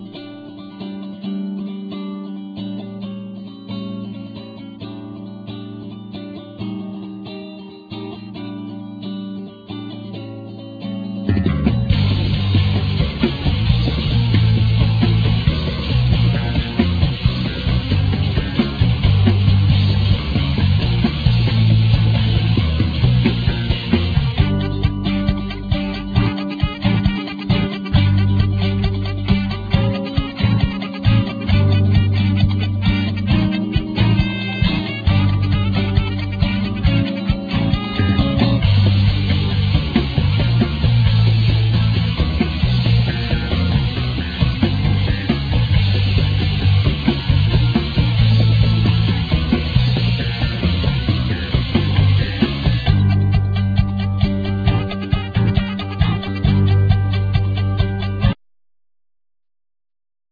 Guitar,Keyboards,Percussion,Timpani,Tublar bells
Bass,Vocal
Drums,Vocal